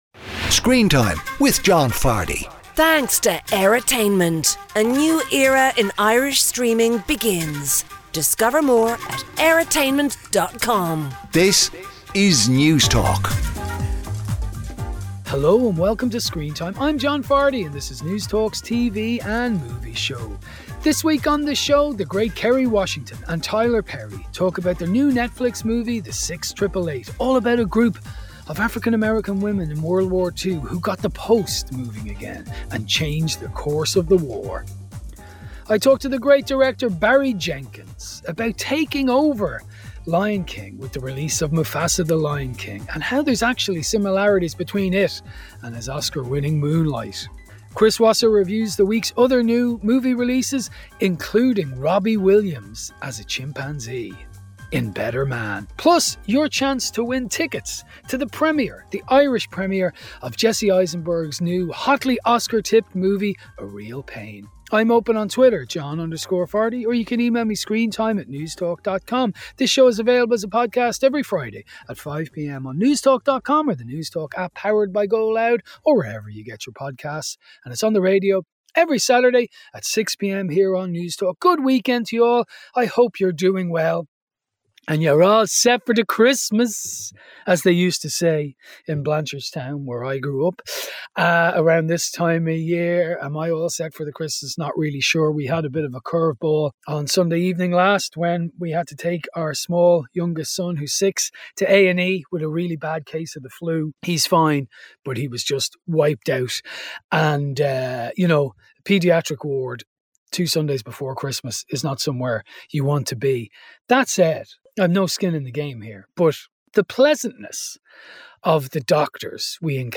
With interviews, reviews and lots of movie and TV treats